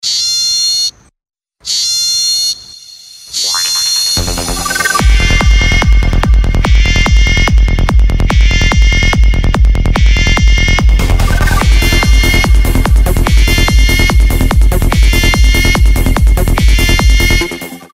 звуки животных , psy-trance